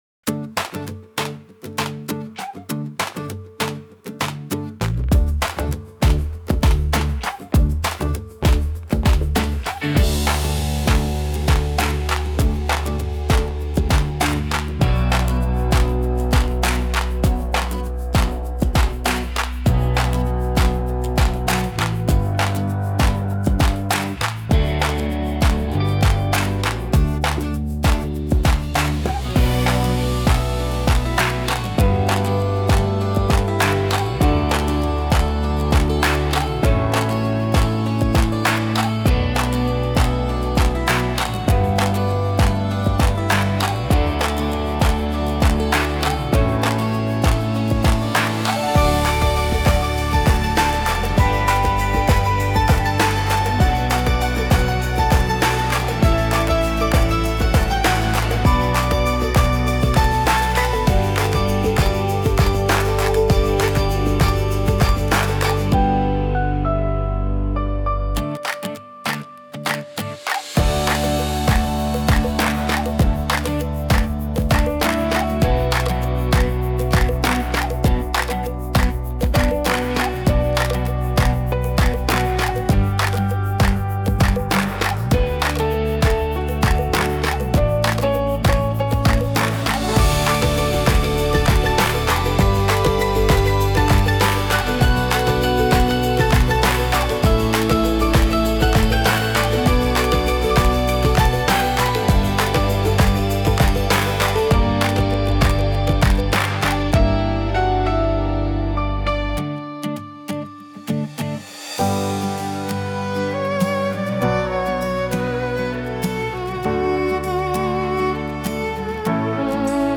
著作権フリーオリジナルBGMです。